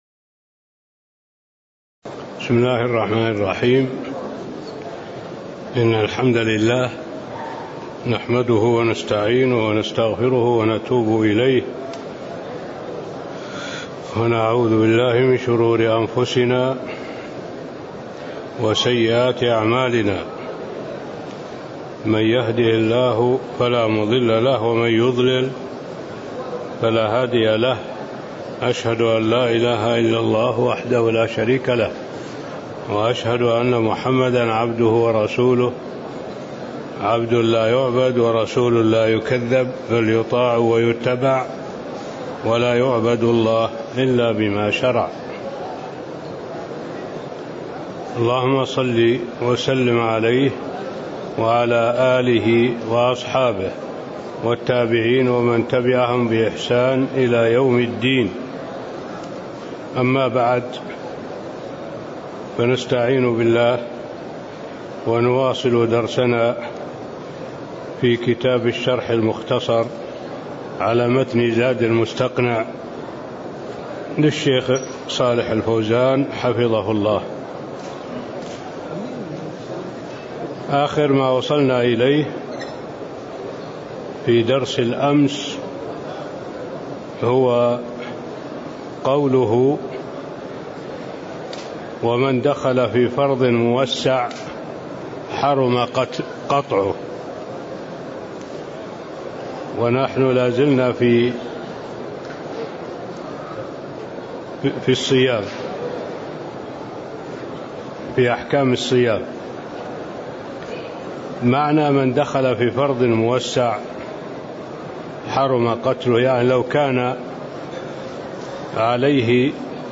تاريخ النشر ٢٢ شعبان ١٤٣٤ هـ المكان: المسجد النبوي الشيخ